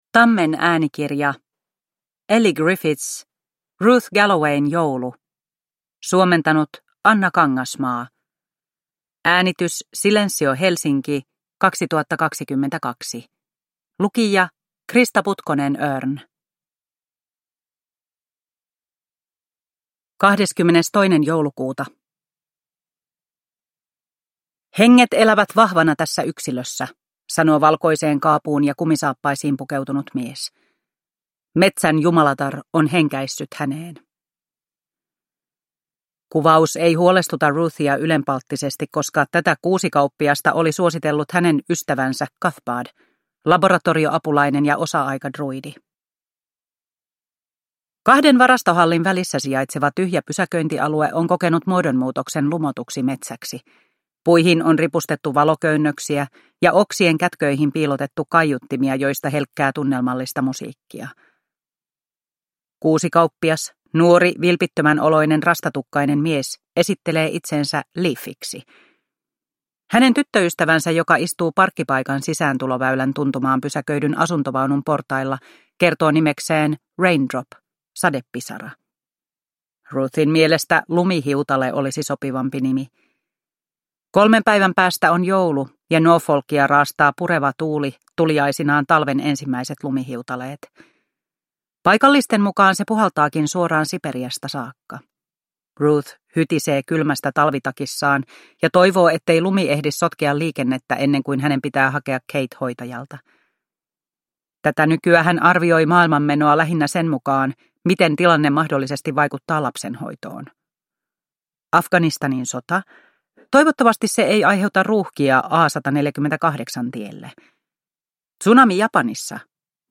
Ruth Gallowayn joulu – Ljudbok – Laddas ner